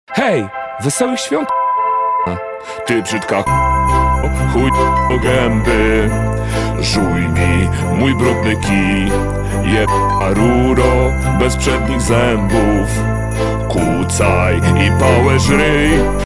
Poniżej znajdziesz ocenzurowany fragment utworu: